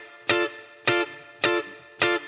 DD_TeleChop_105-Dmin.wav